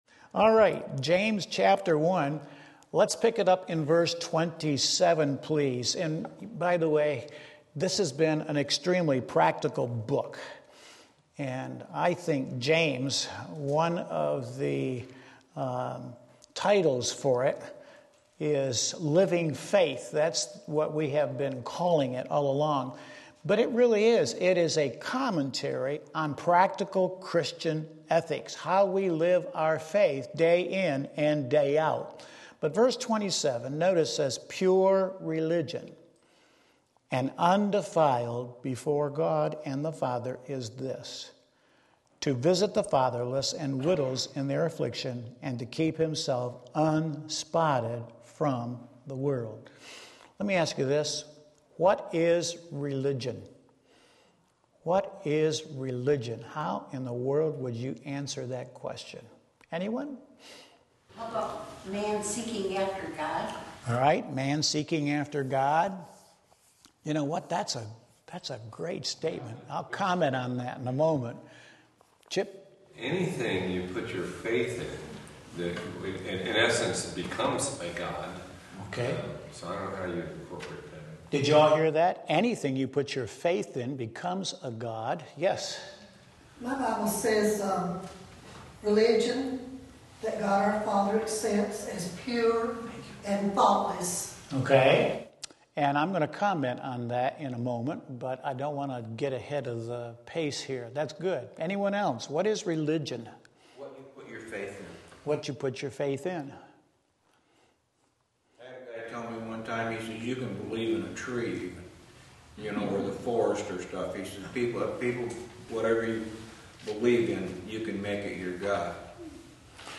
2:20-25 Sunday School